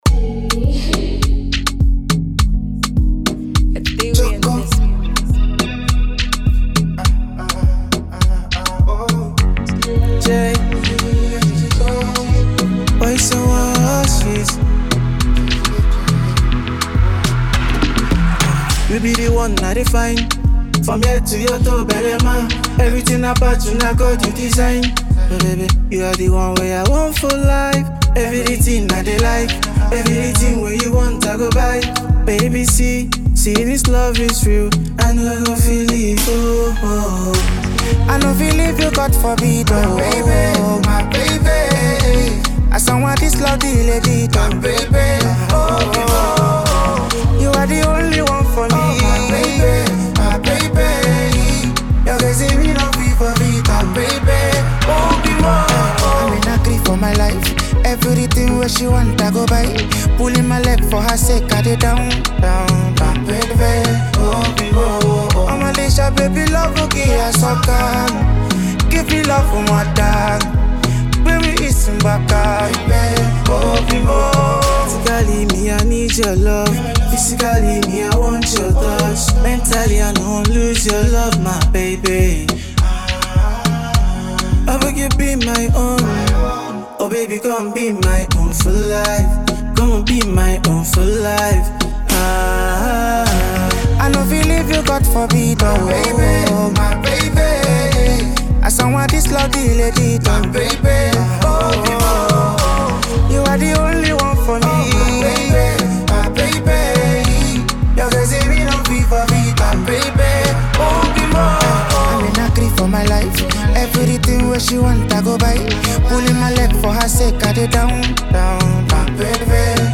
Nigerian singer-songwriter and performer